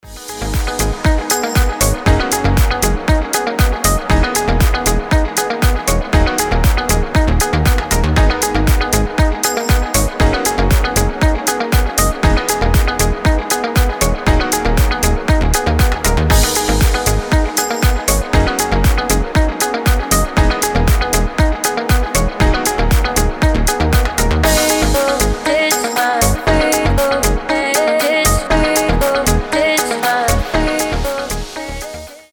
красивые
deep house
мелодичные